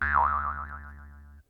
cartoon_boing_or_spring_jaw_harp_